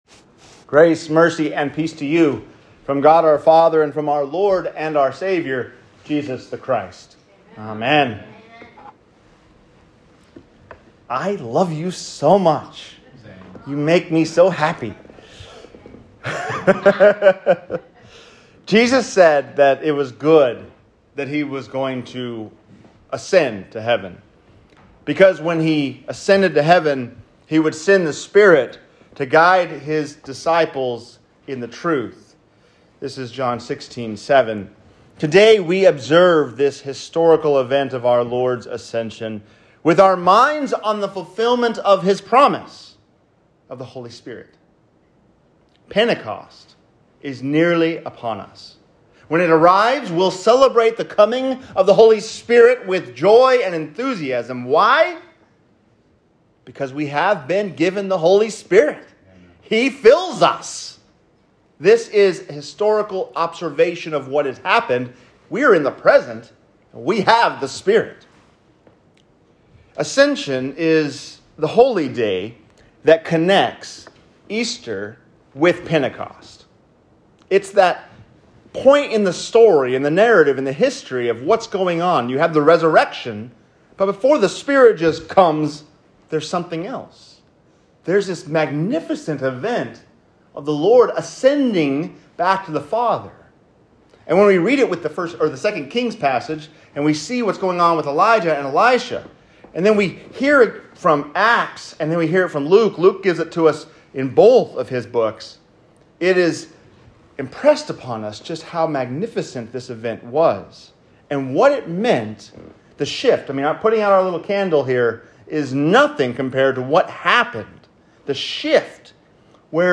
The Ascension of Christ | Sermon
5-26-22-sermon_ascension.m4a